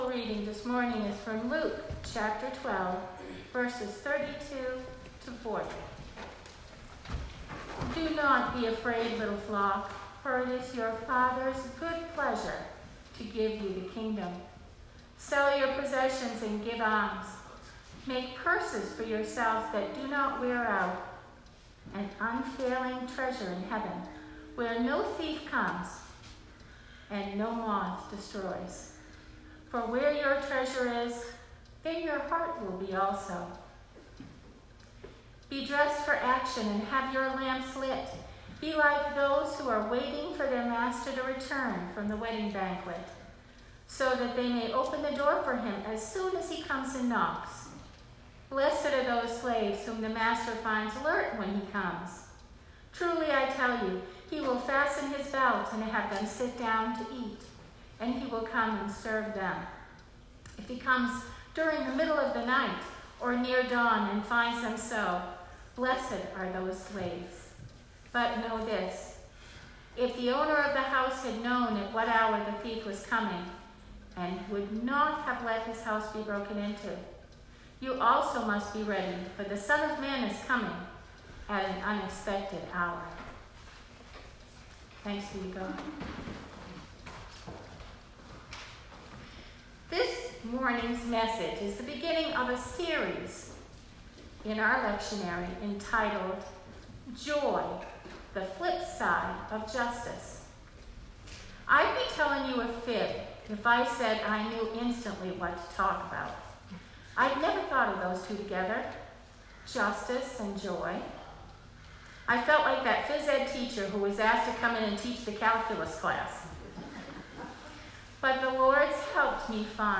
Sermon 2019-08-11